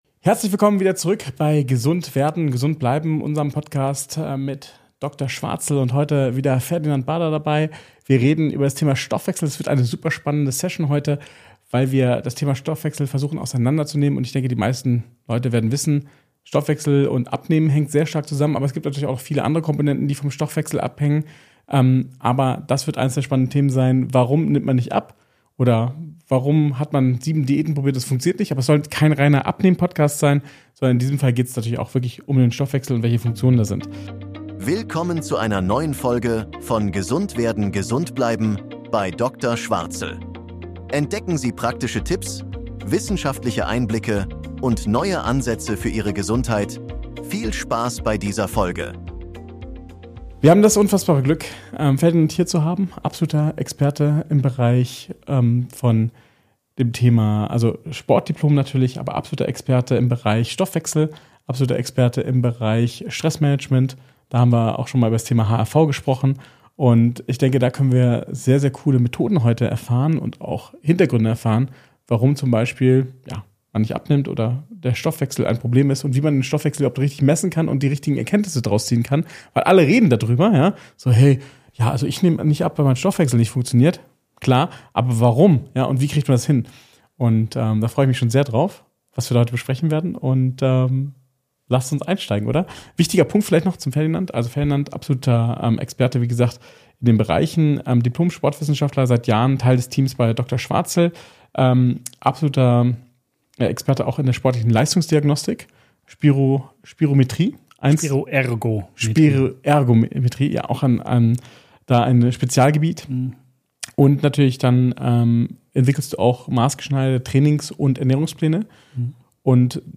Die Wahrheit über Ihren Stoffwechsel: Mythen & Fakten | Interview